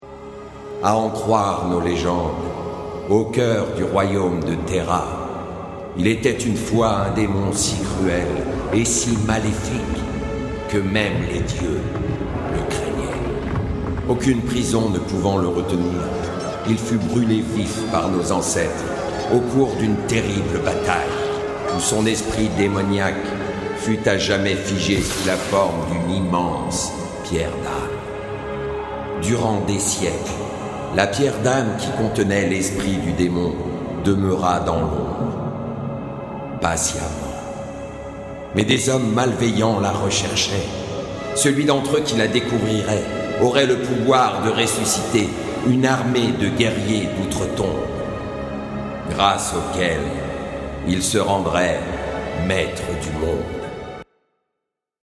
Voix off